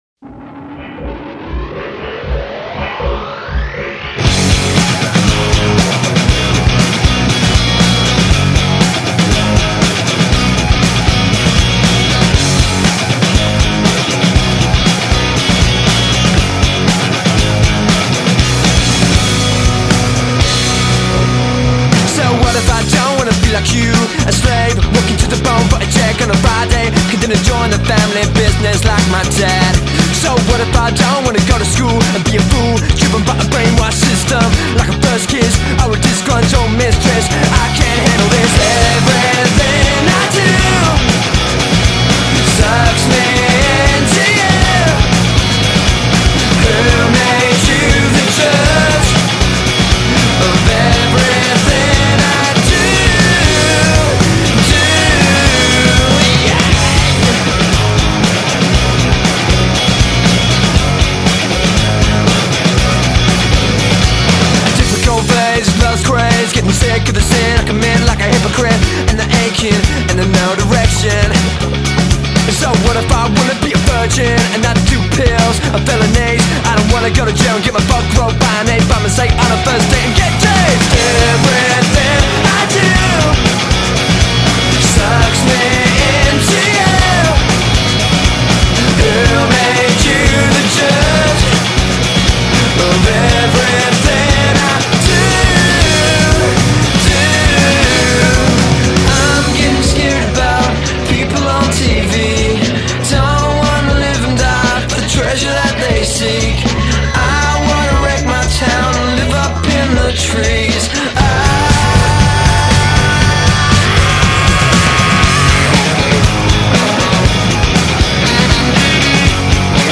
Remixed for American radio/album
However - the subtle changes in the mix sound pretty cool.